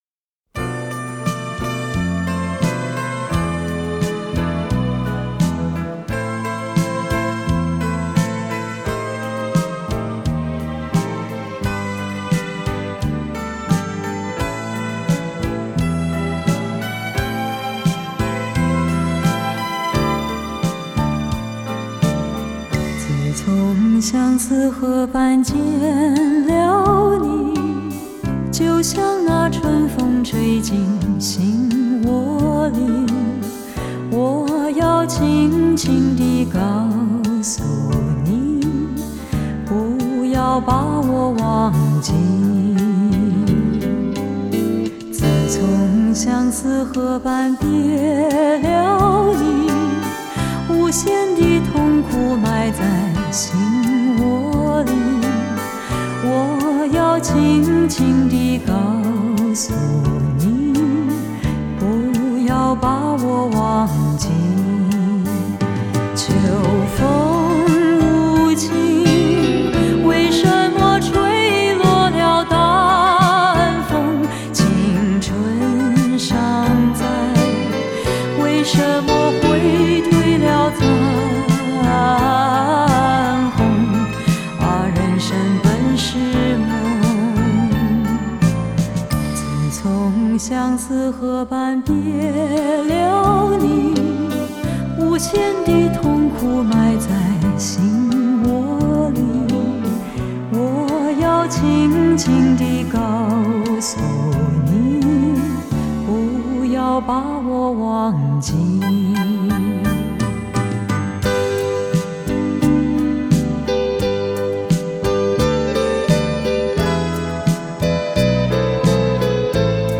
国语流行